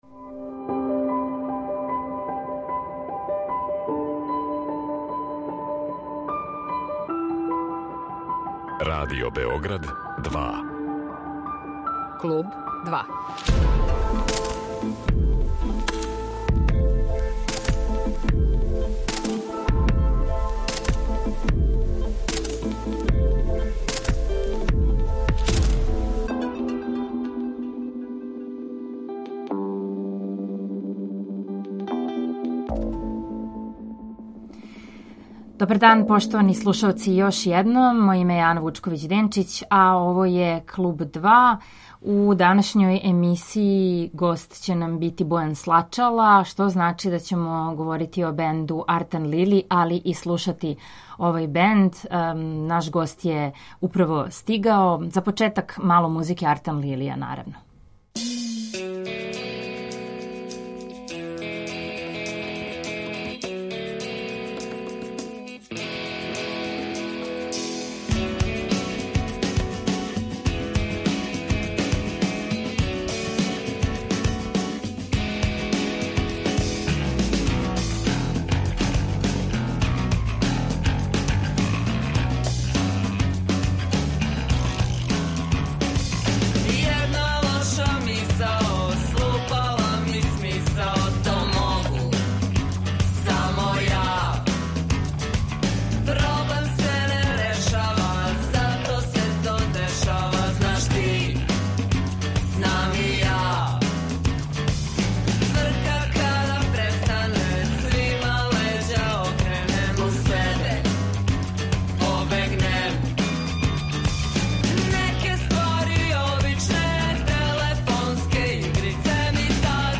Слушаћемо музику, али ћемо и разговарати о томе како створити певљиву мелодију, хит, биће речи о пријатељству и вишегласју, о радости прављења музике, наступања, о изузетно добром пријему музике Артан Лилија код публике.